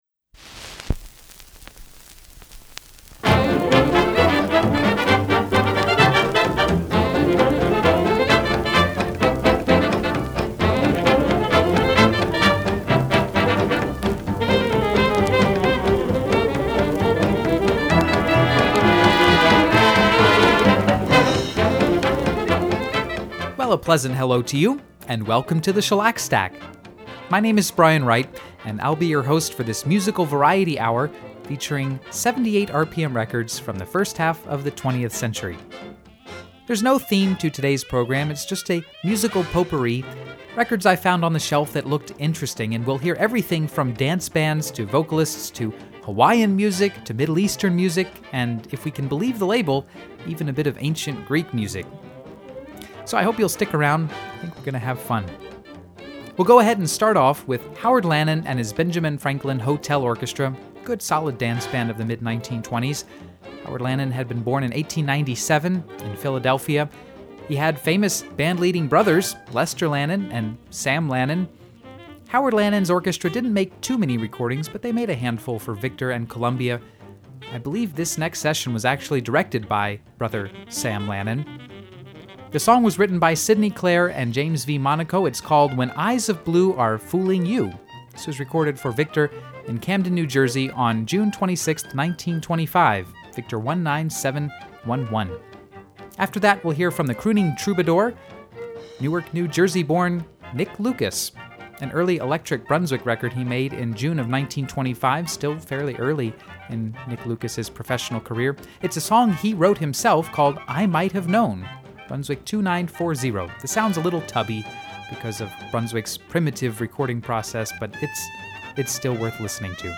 dance bands
some hot steel guitar
the enchanting voice
early ragtime